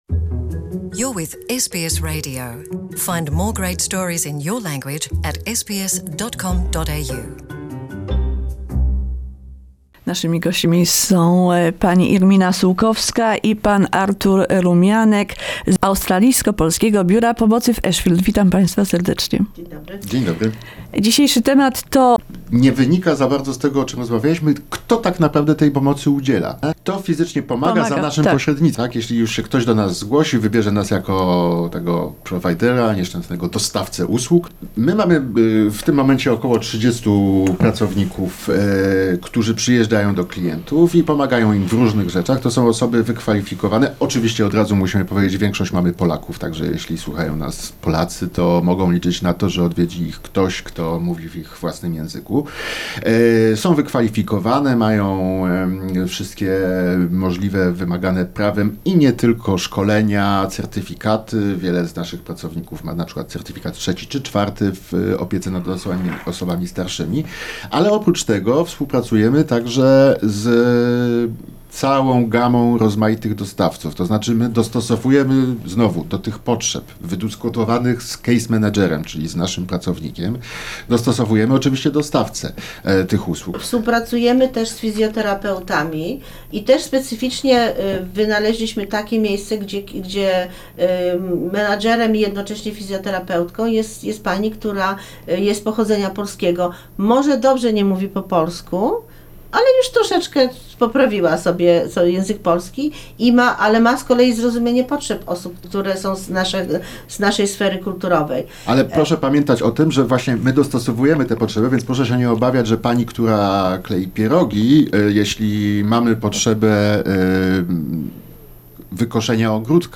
This is part 5 of the interview.